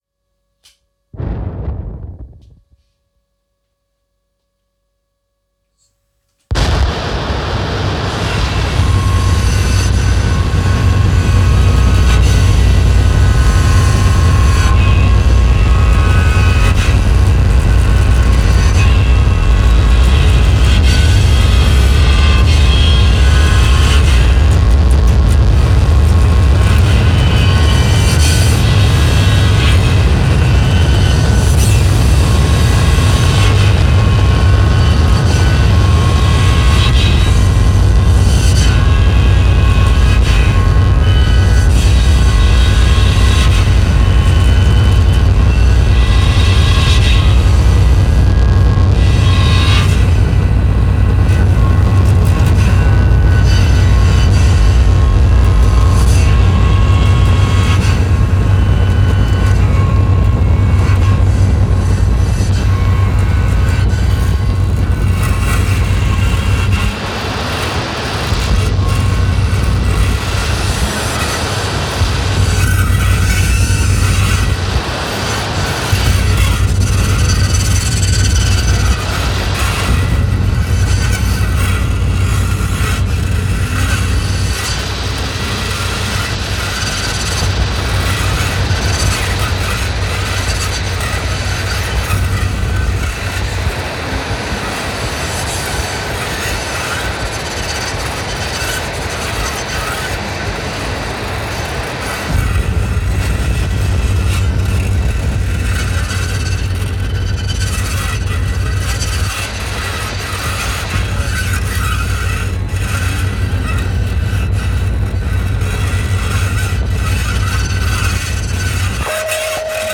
Studio version
electro-acoustic music
sound art